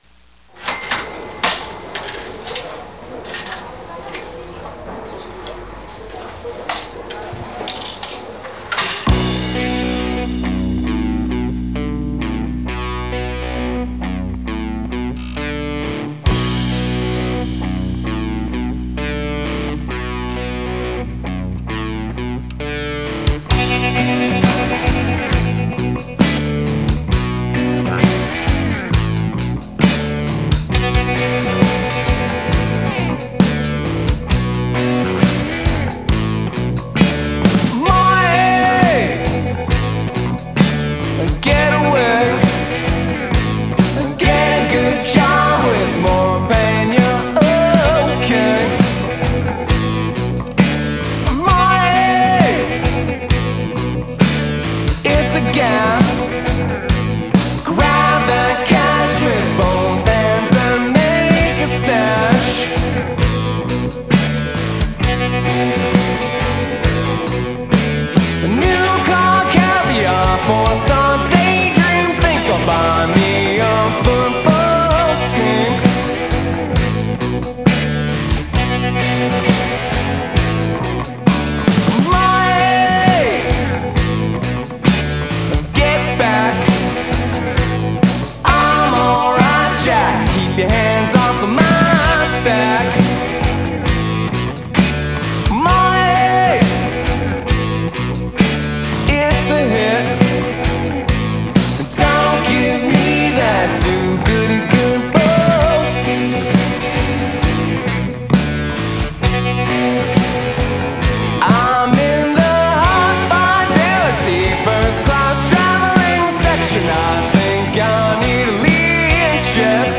laid back, high energy, vintage, retro, modern, classic rock